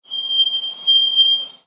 brake2.mp3